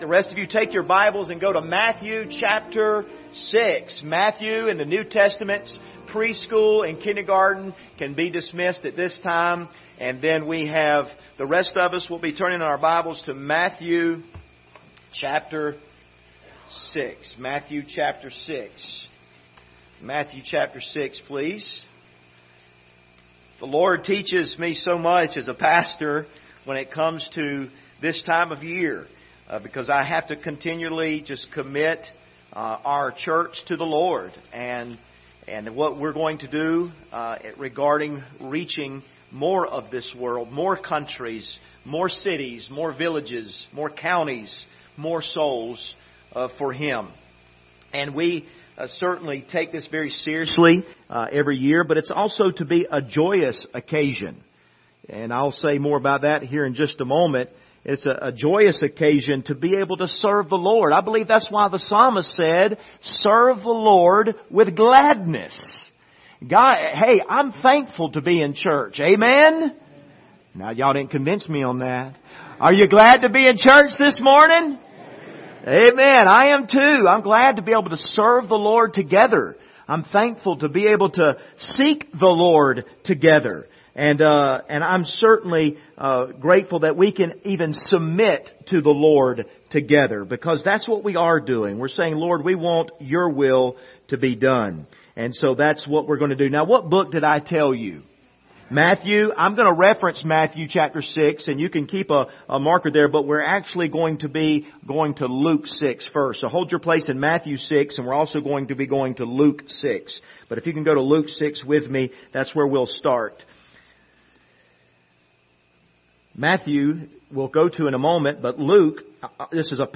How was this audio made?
Passage: Luke 6:38 Service Type: Sunday Morning